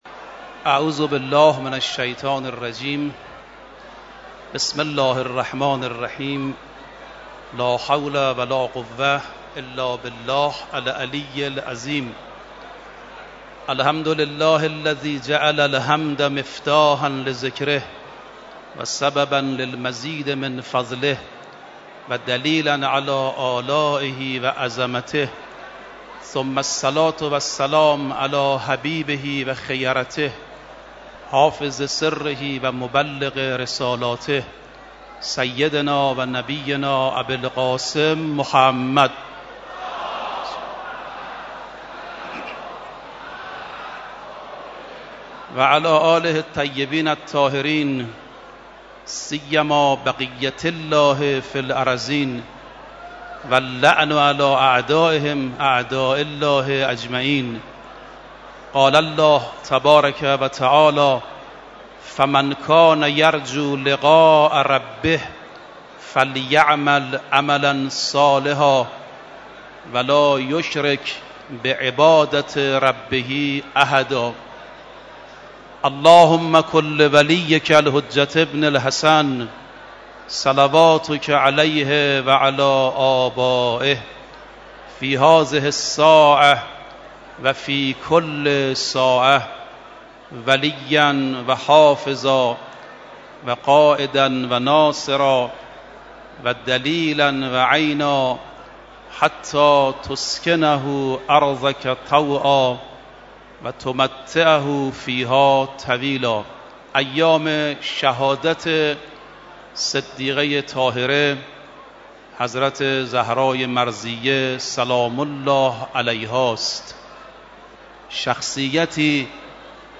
در ادامه صوت سخنرانی این کارشناس مذهبی را می شنوید: